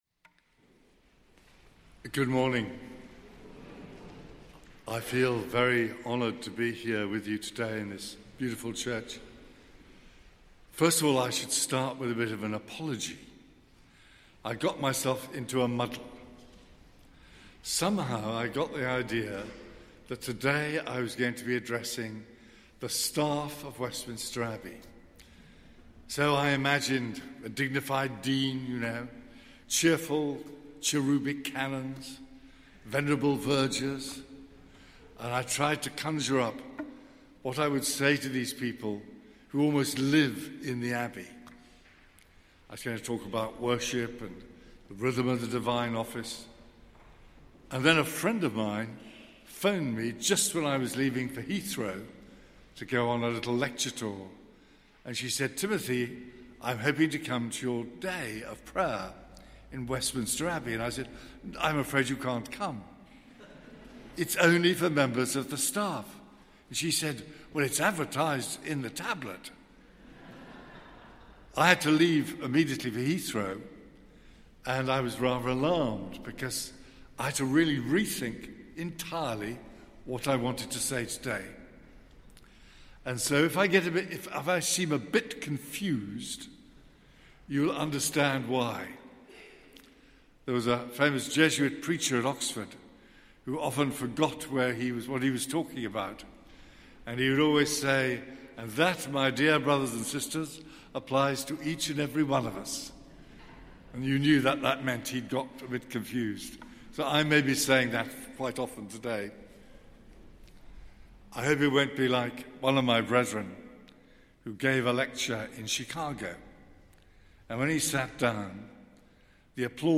Fr Timothy Radcliffe OP, a Dominican friar and Roman Catholic priest, explores how prayer in its different forms shapes us in faith, hope and love.